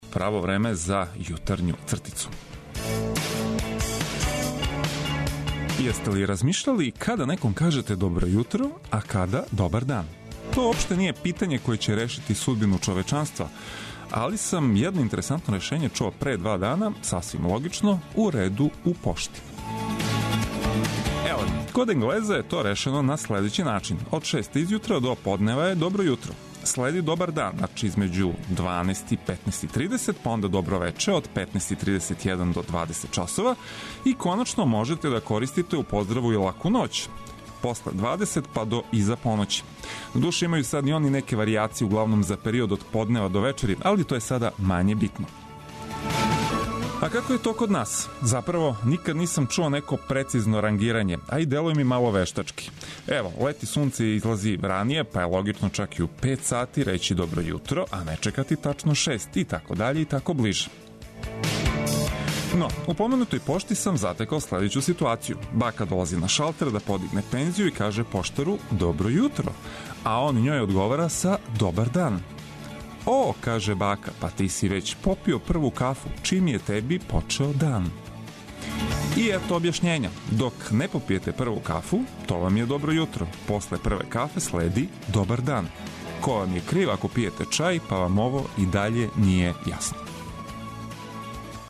Наравно, уз вашу помоћ - слушалаца репортера, али и одличну музику са свих страна света!